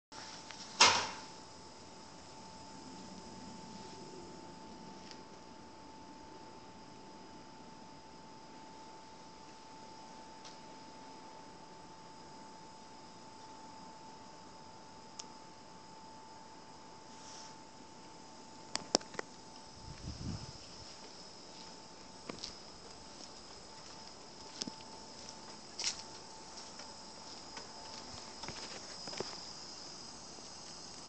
Field Recording Numero 6
Location: 3/14/2014, 9:40A, Before Emily Lowe Hall.
Sounds Featured: Birds chirping, shoes on the sidewalk, pneumatic whining of pipes